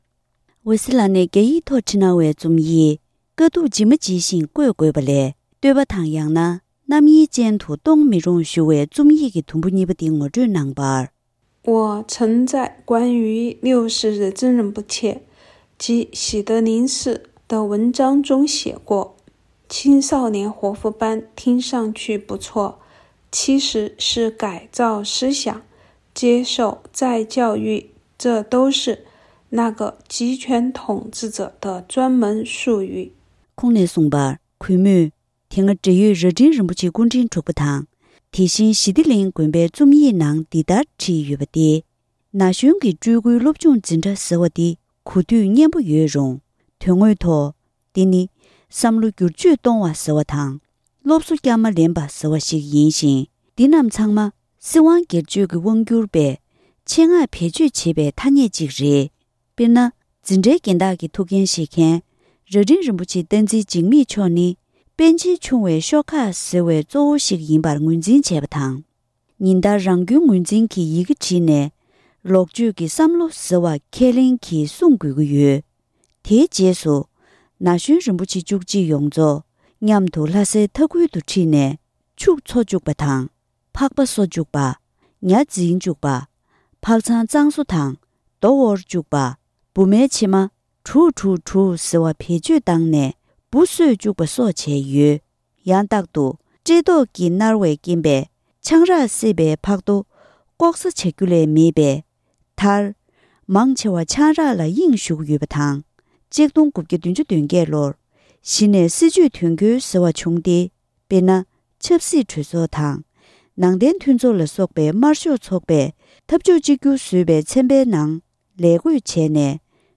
དཀའ་སྡུག་ཇི་མ་ཇི་བཞིན་བཀོད་དགོས། གཉིས་པ། སྒྲ་ལྡན་གསར་འགྱུར།